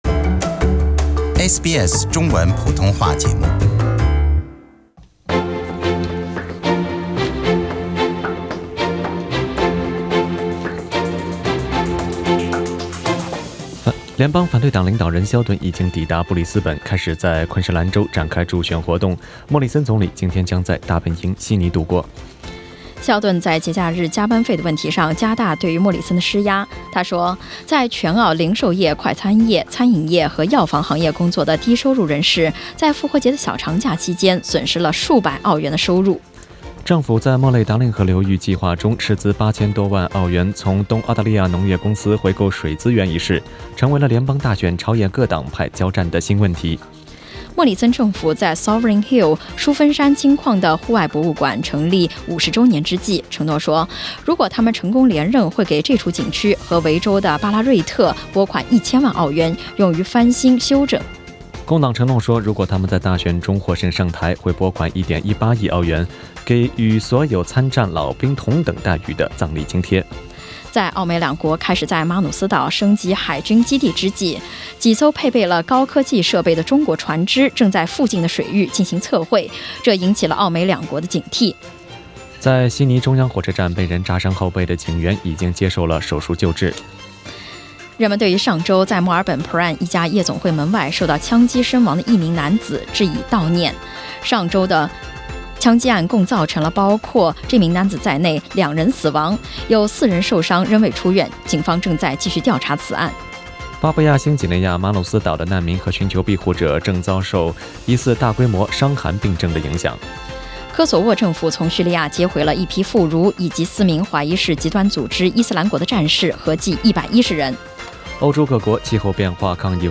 SBS早新闻（4月21日）